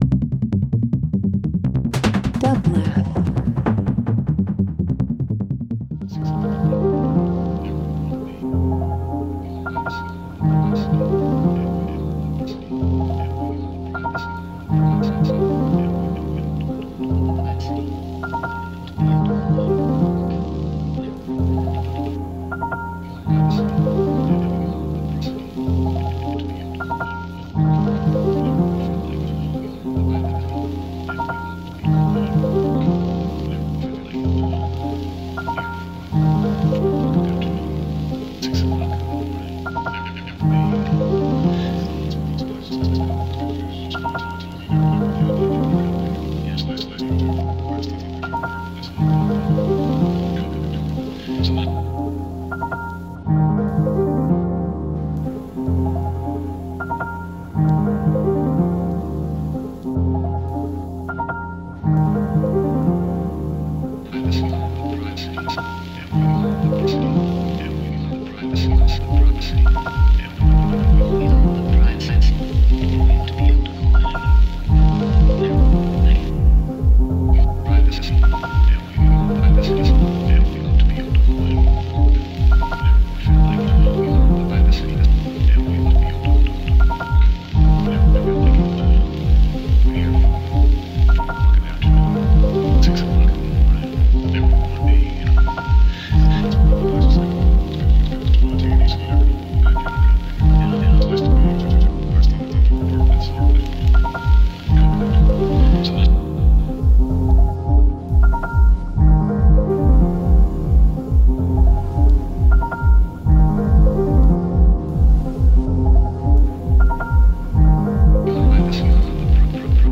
Ambient Dub Electronic Footwork Techno